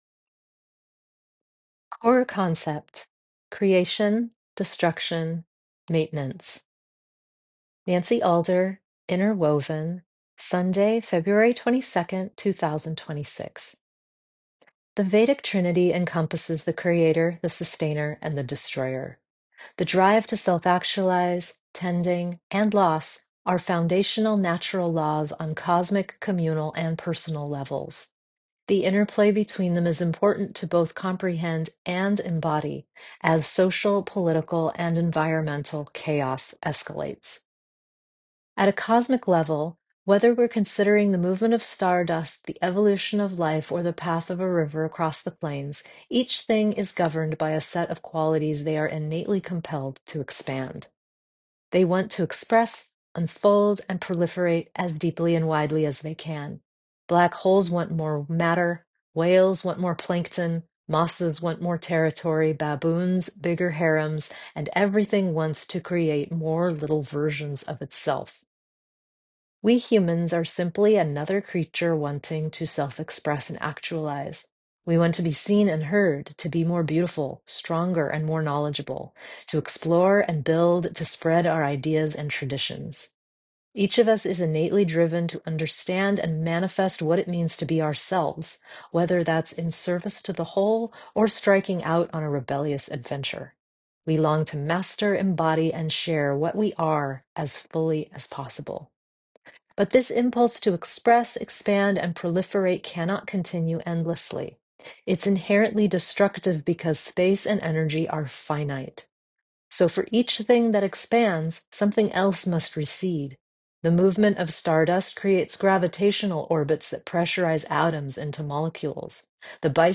Enjoy this 8-minute read or let me read it to you here